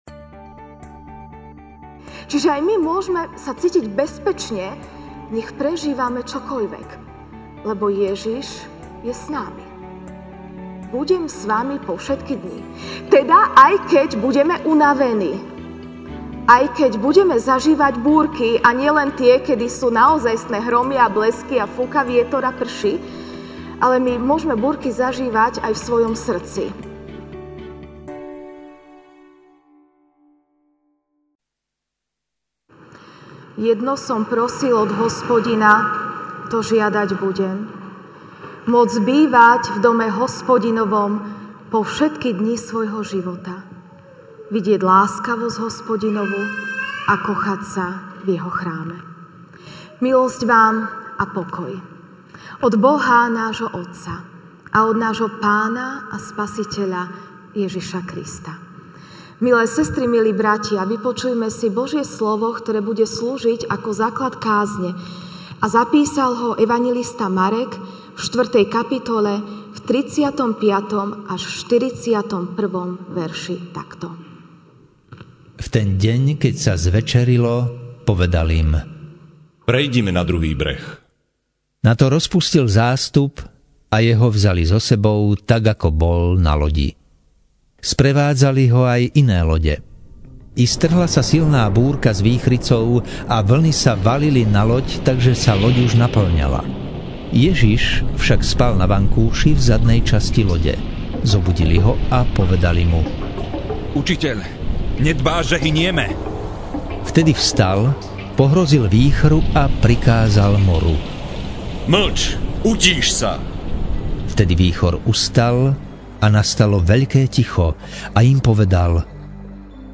sep 08, 2019 Búrka na mori MP3 SUBSCRIBE on iTunes(Podcast) Notes Sermons in this Series Ranná kázeň: Búrka na mori (Mk 4, 35-41) V ten istý deň, keď sa zvečerilo, povedal: Prejdime na druhú stranu!